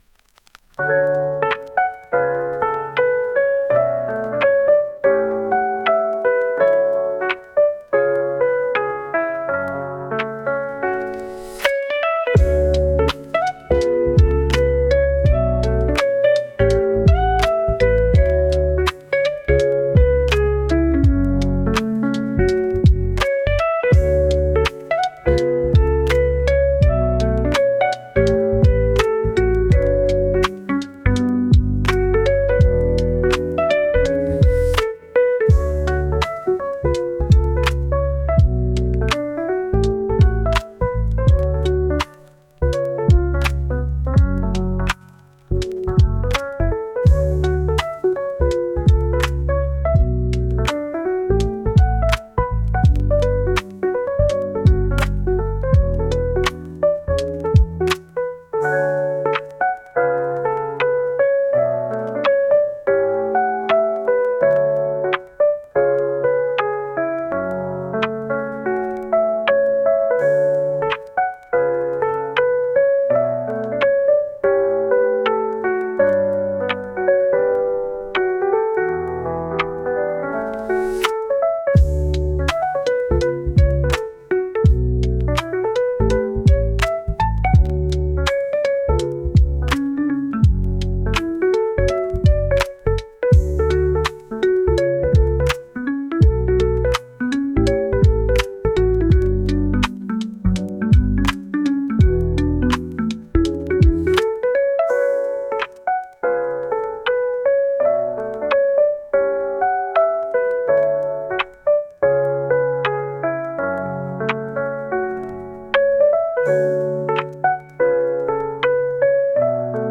夜まどろみたいのに眠くないピアノ曲です。